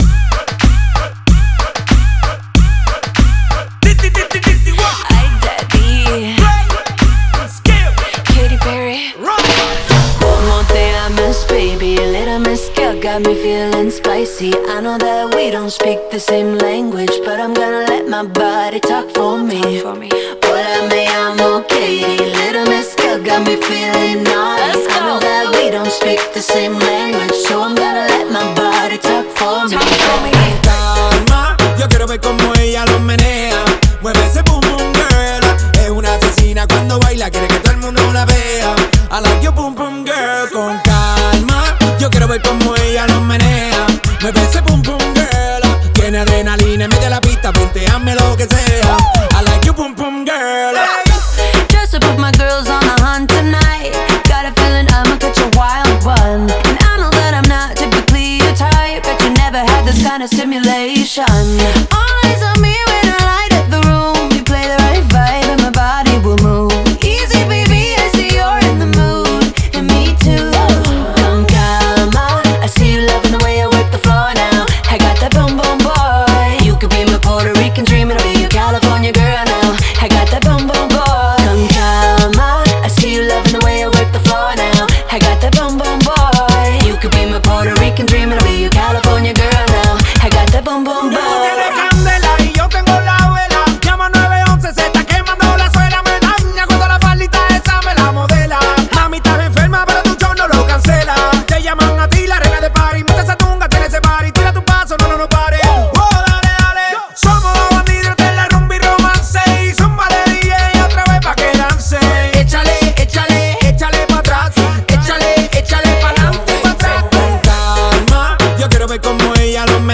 بیس دار